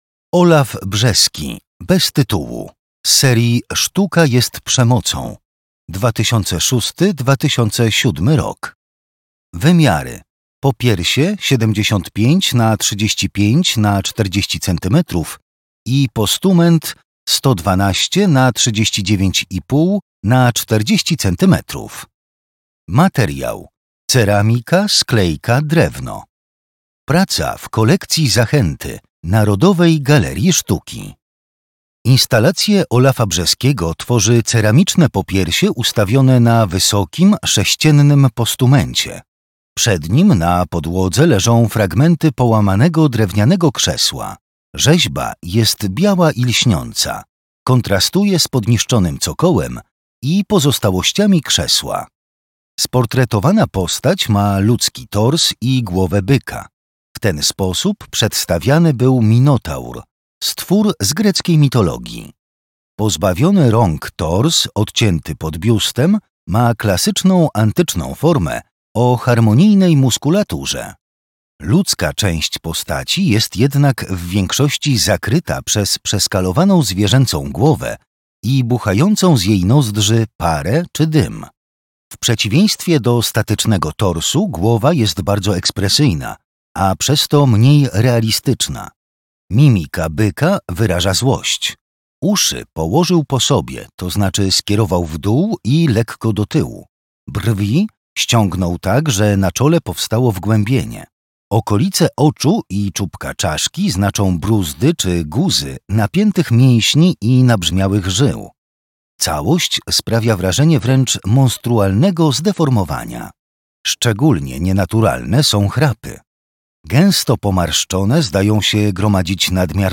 audiodeskrypcja
lektor